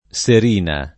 Serina [ S er & na ]